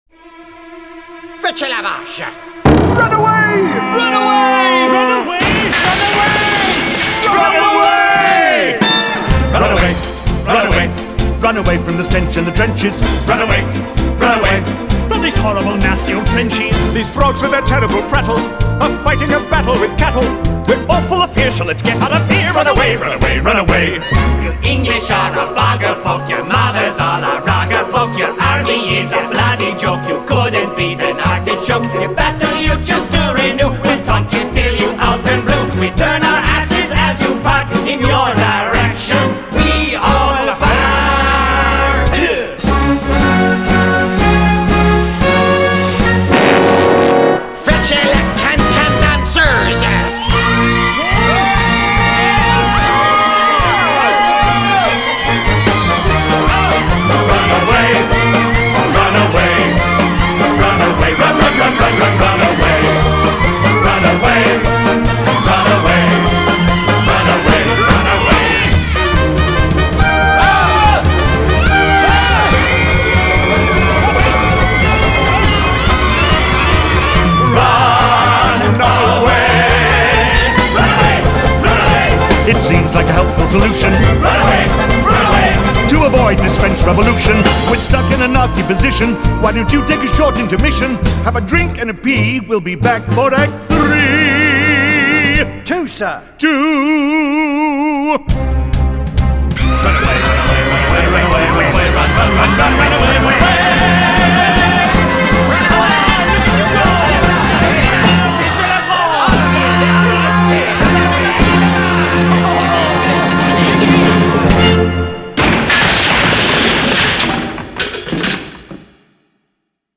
Genre: Musical.